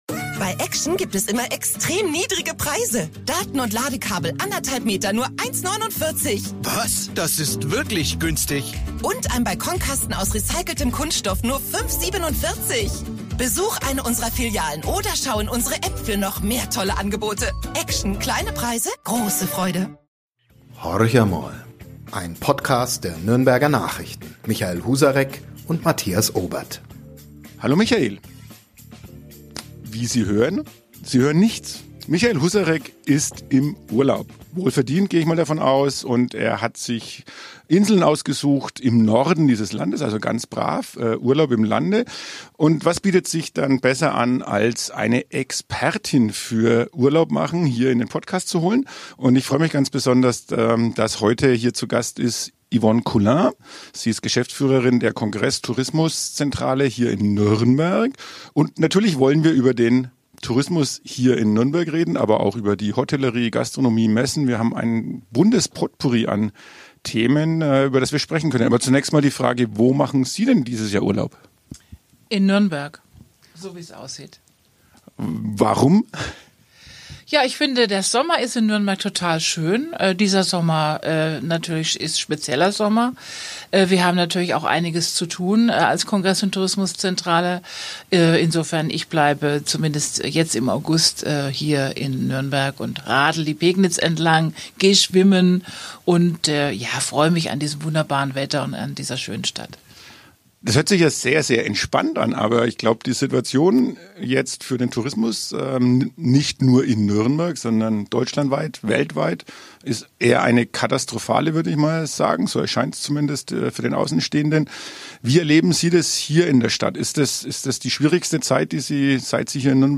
Ein Gespräch über Zuversicht, den Christkindlesmarkt und die Zukunft des Tourismus.